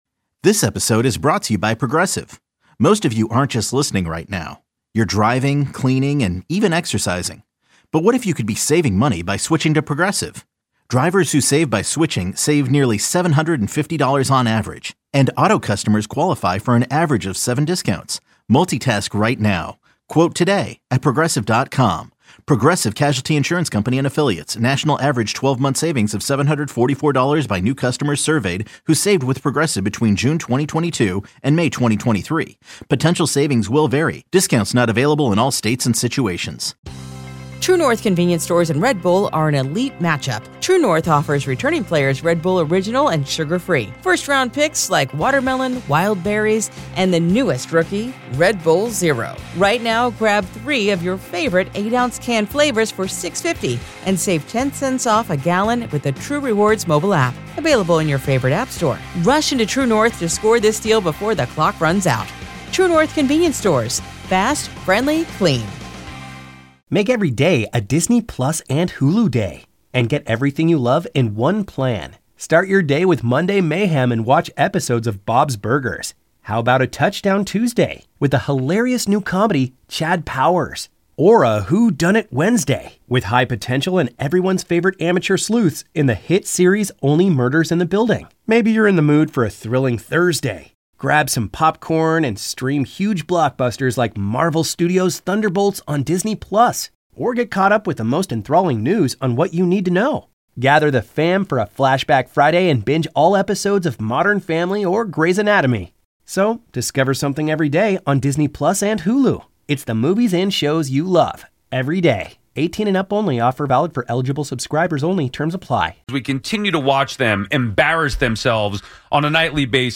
The discussion concluded with both hosts expressing frustration and disgust over the team's on-field performance and a sense that the Mets are simply accepting their fate.